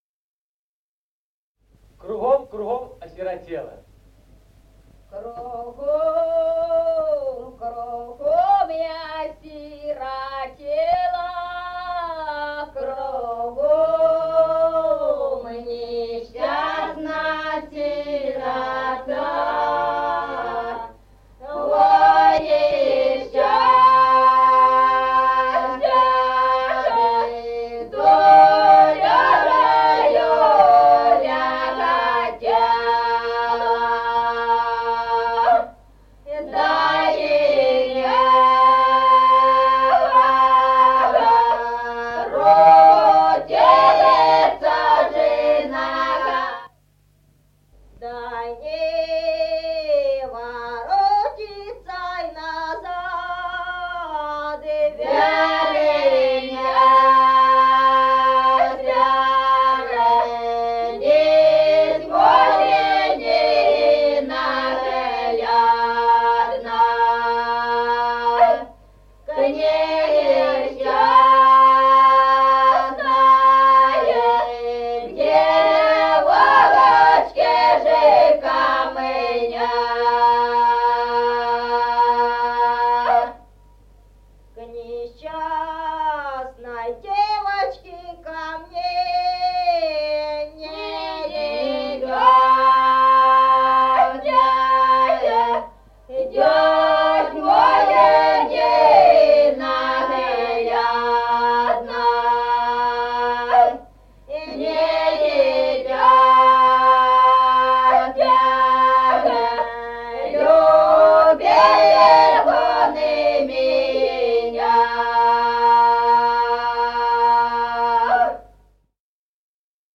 Музыкальный фольклор села Мишковка «Кругом, кругом осиротела», лирическая.